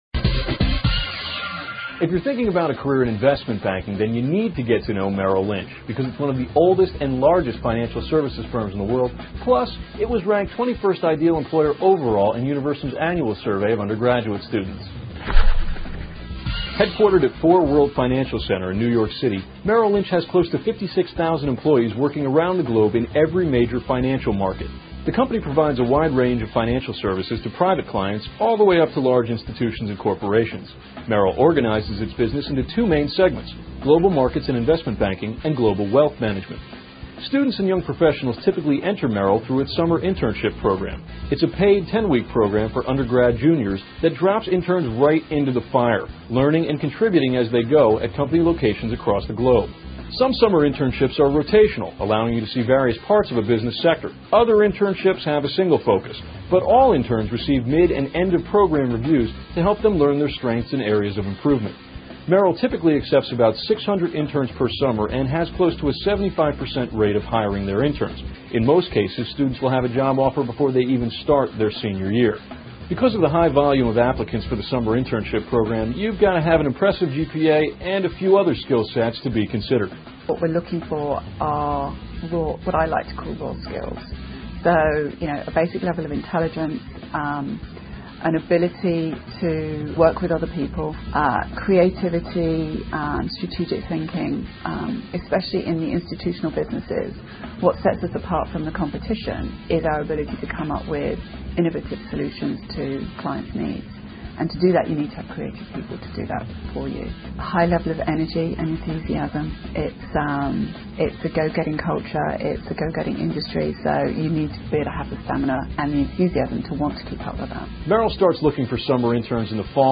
访谈录[Interview]2007-12-29:求职访谈之：美林：投行中的巨人 听力文件下载—在线英语听力室